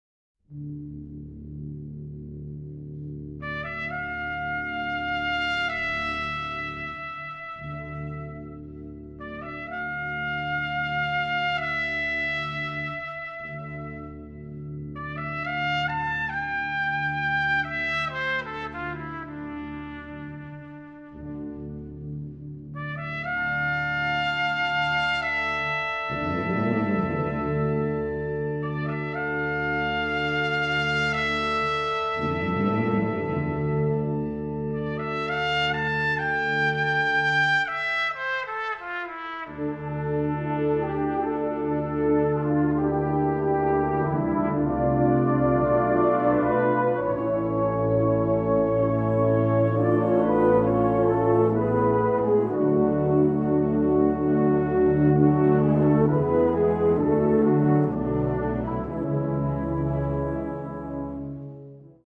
Voor Trompet en Fanfare.